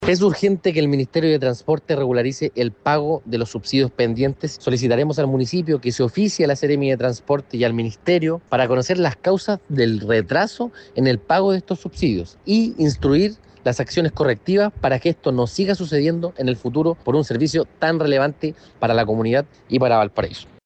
Por su parte, el concejal Vicente Celedón confirmó que solicitarán a la Municipalidad que se oficie a la Seremi y al Ministerio de Transportes para regularizar con urgencia los pagos.